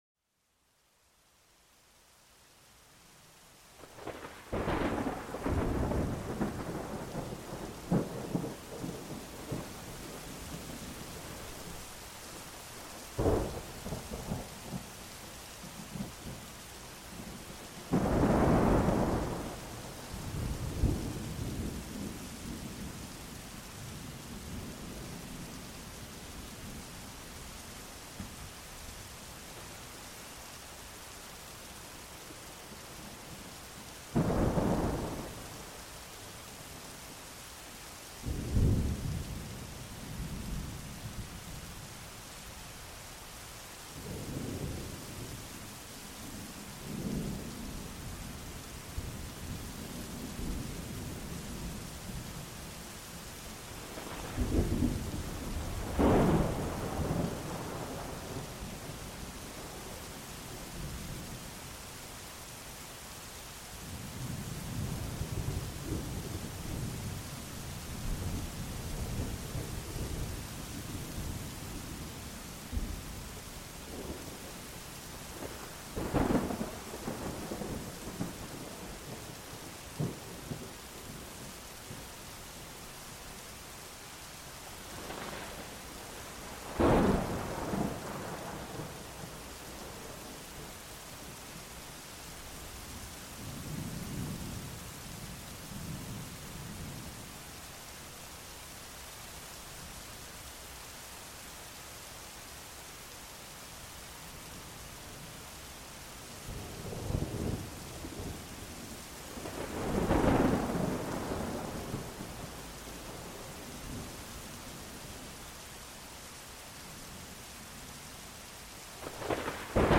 Plongez au cœur d'un orage majestueux et découvrez la beauté enveloppante du tonnerre. Chaque éclair nous offre une symphonie unique, où la puissance de la nature se mêle à une tranquillité inattendue.